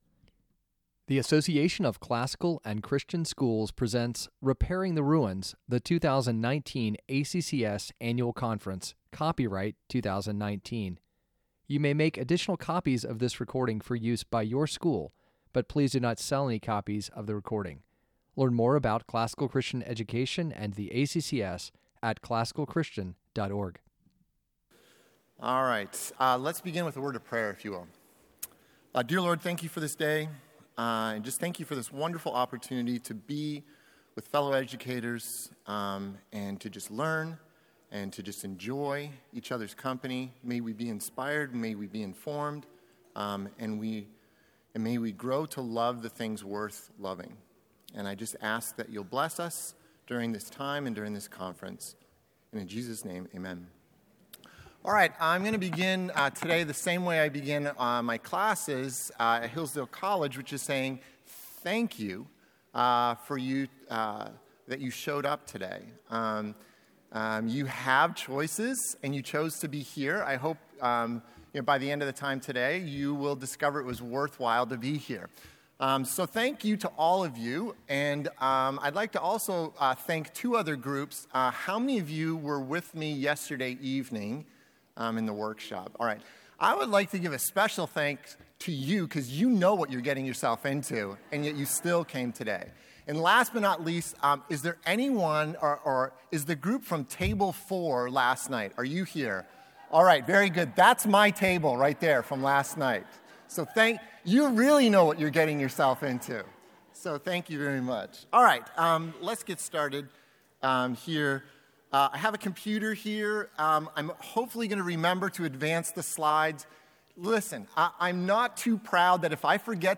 2019 Workshop Talk | 52:47 | All Grade Levels, Literature, Virtue, Character, Discipline